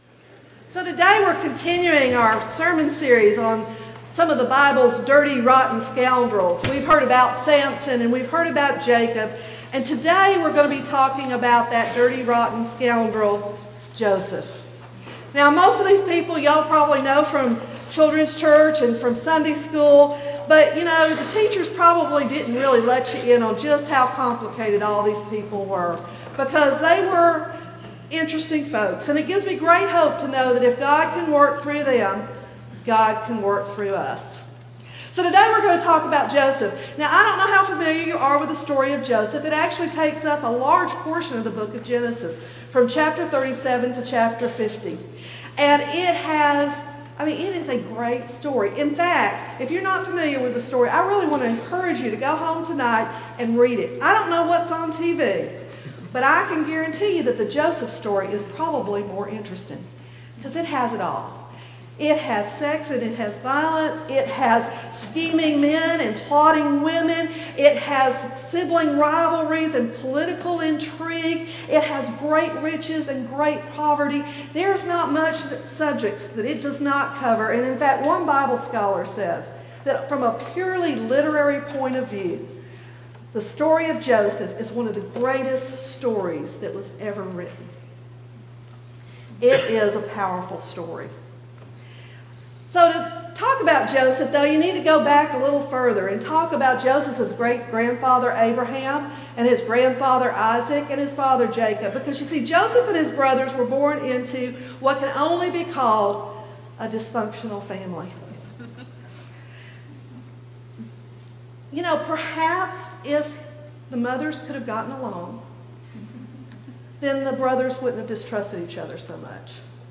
Aldersgate United Methodist Church Sermons